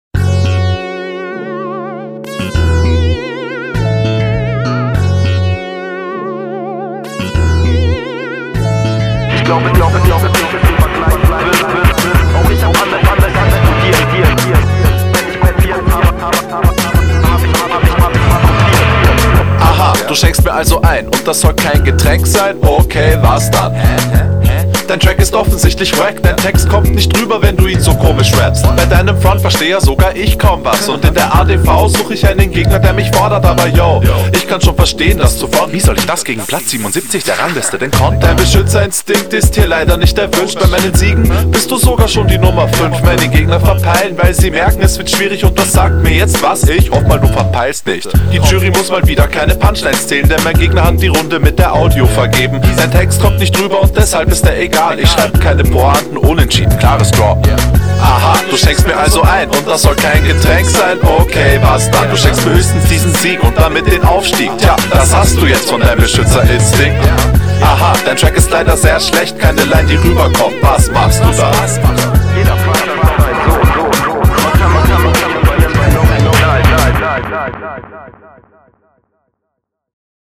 Die Soundmische ist auch schlecht ich fand nämlich die Stimme zu übersteuert dennoch besser als …
Servus finde deinen Style hier etwas gelangweilt, du hättest definitiv Stimmlich einen draufsetzen können.